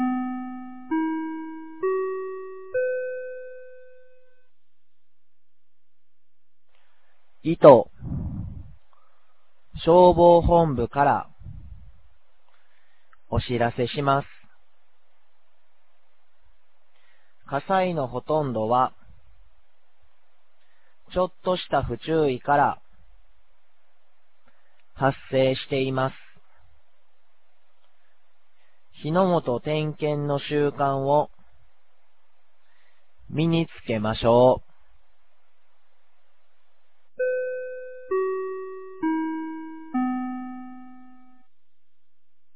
2026年03月30日 10時02分に、九度山町より全地区へ放送がありました。